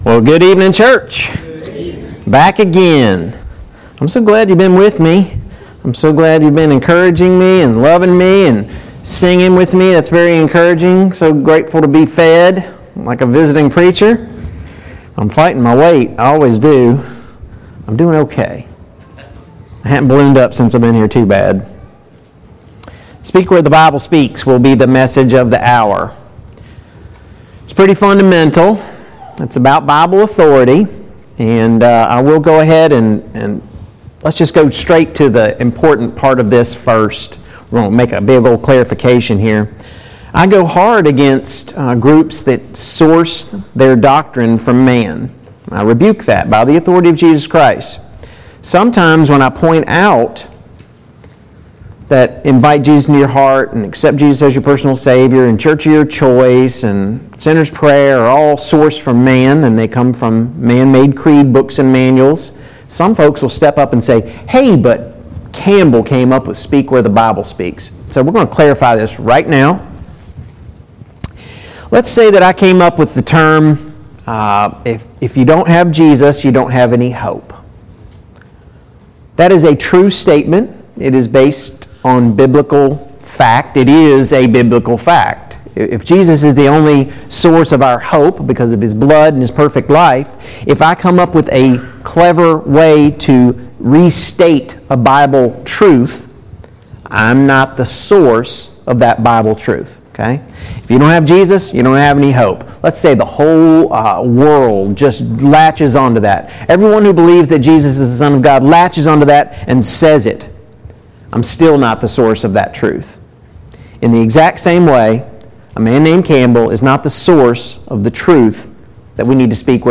2022 Spring Gospel Meeting Service Type: Gospel Meeting Download Files Notes Topics: Biblical Authority « 3.